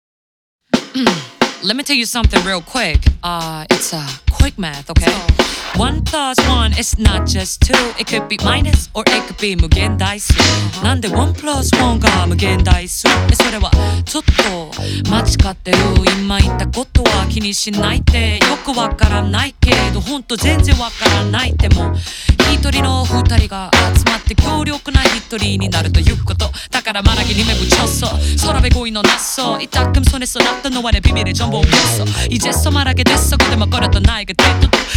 Жанр: Поп музыка / Альтернатива
Alternative, J-Pop